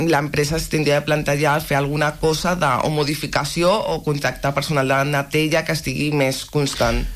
L’any d’activitat de la Sindicatura Municipal va de juliol a juliol, i a l’espera del balanç oficial de l’últim i en seu plenària, Natàlia Costa n’ha donat detalls en una entrevista a l’FM i + de Ràdio Calella TV.